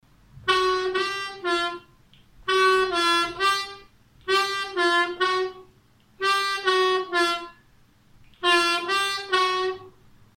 Con este ejercicio aprendemos a controlar los varios grados de bending en el agujero 2 aspirado.
Tonalidad de la armónica: C
AHCOD - Audio_Hole 2 draw bending routine phrase 3.mp3